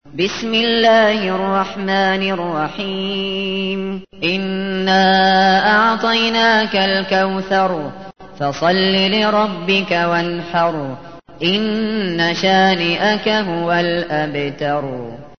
Audio icon ترتیل سوره کوثر با صدای سعود شاطرى ازعربستان (62.13 KB)